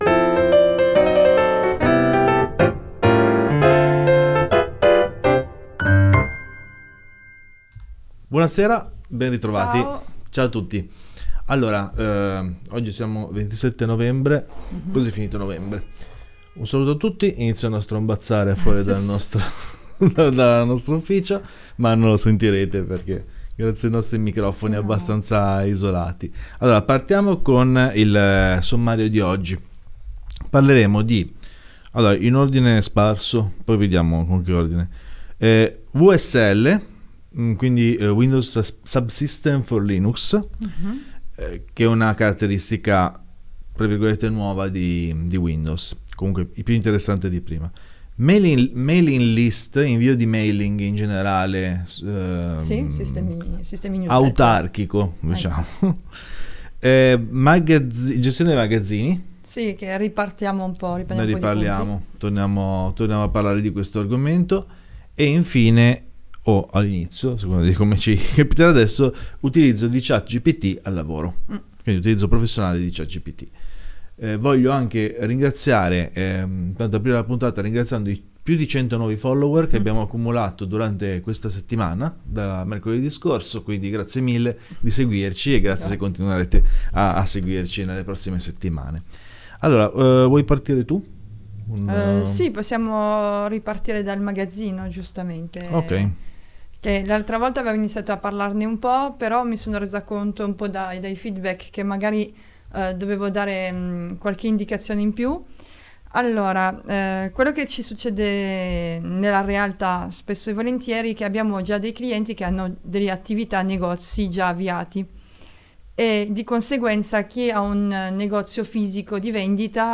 La “week in review” in diretta dalla nostra sede di Torino.